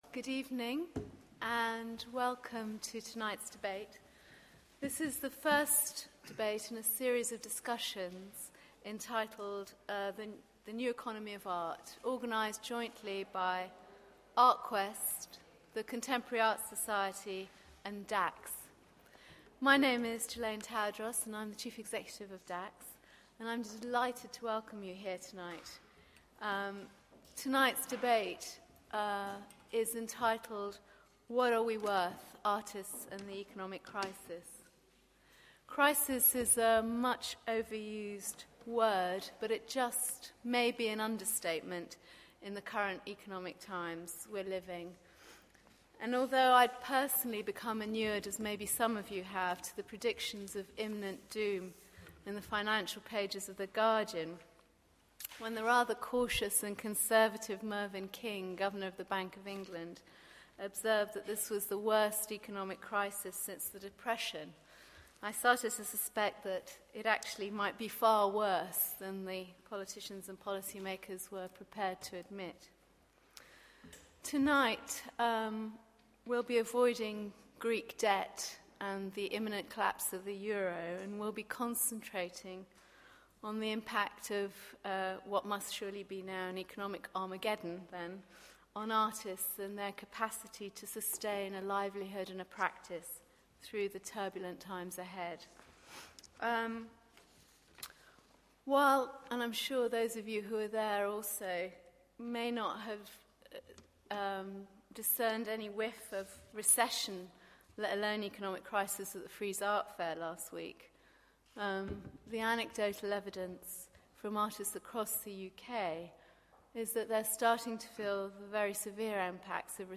Artists and the Economic Crisis Part of the New Economy of Art series When: 18 October 2011, 6:30 PM - 8:00 PM Where: Conway Hall, 25 Red Lion Square, London WC1R 4RL What are we worth? looked at how artists can create income in support of their practice in a period of dramatic economic, social and technological change.
This debate is part of The New Economy of Art – a series of open discussions that focus on the economic developments and opportunities in the cultural sector that impact on artists, from the perspective of artists.